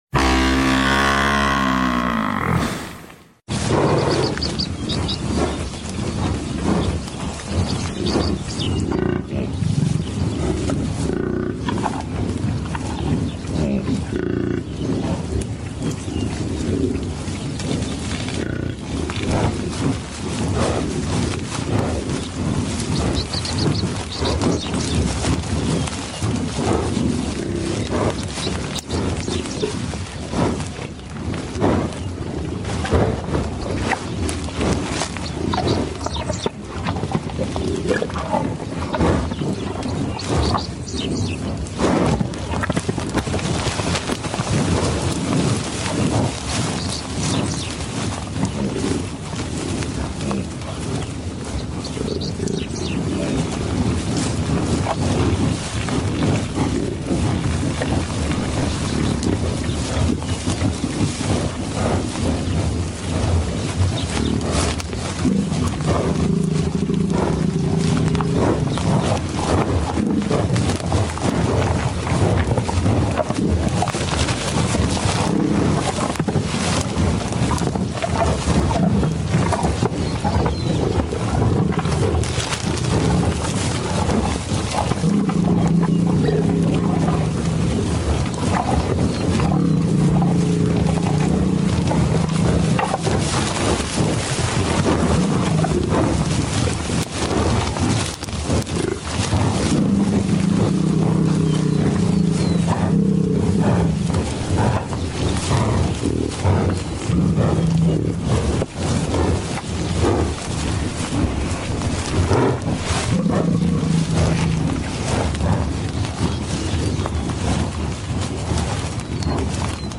Buffalo Sound, Cloud Buffalo Wapaper sound effects free download